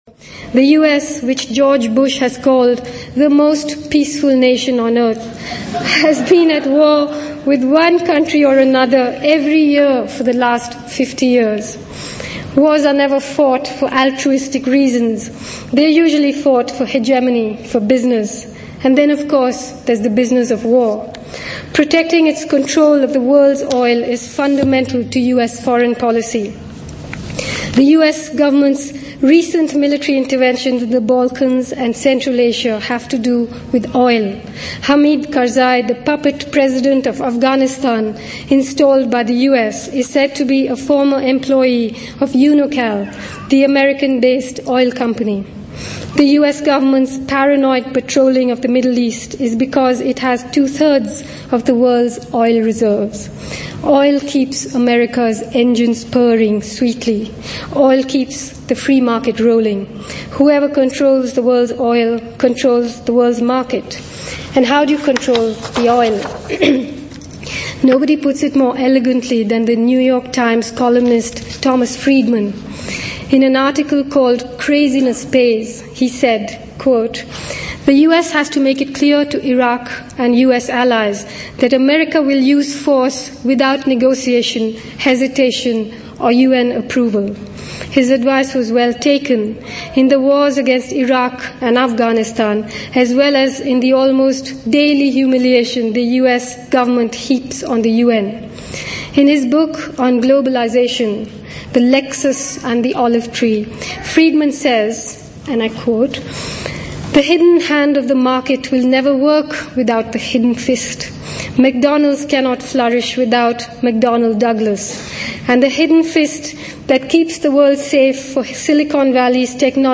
Of hoor haar hier spreken: